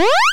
TRORB/Assets/Audio/SFX/jump.wav at main
jump.wav